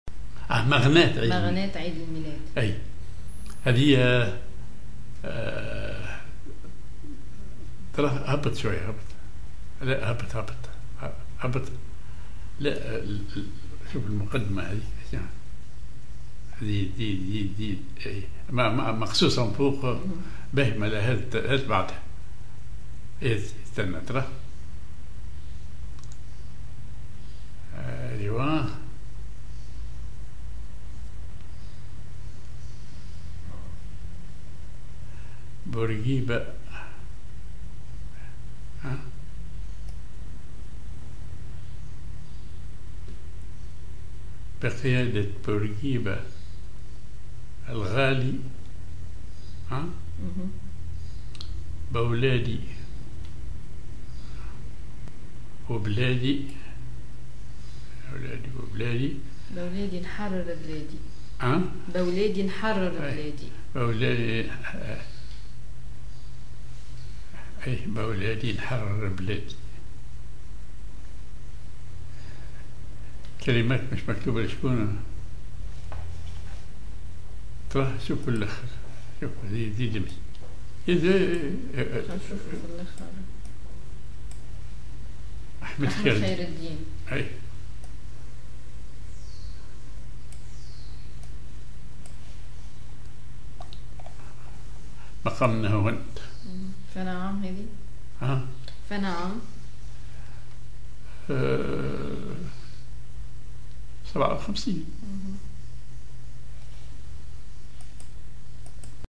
Maqam ar نهاوند
genre أغنية